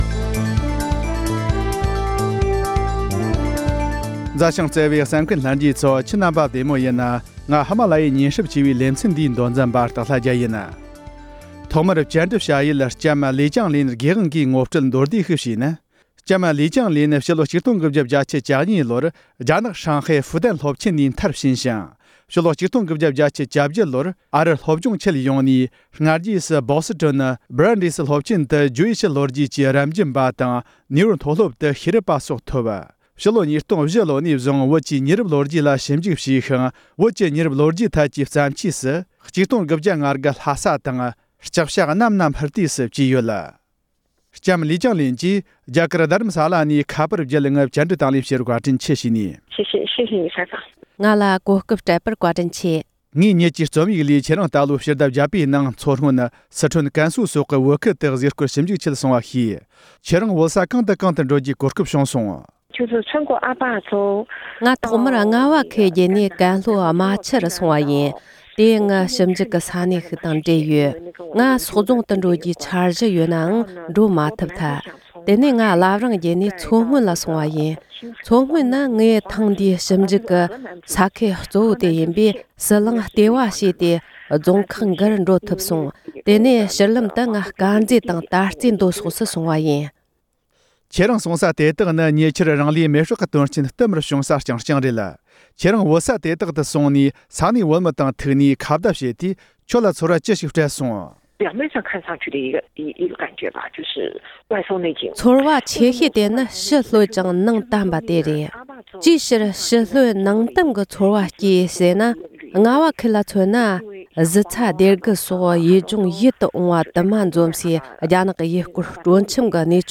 བཅར་འདྲི།